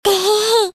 tehehe_51lM2Qh.mp3